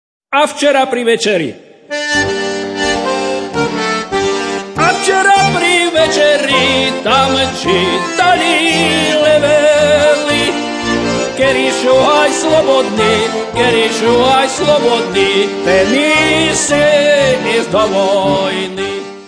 Kategória: Ľudová hudba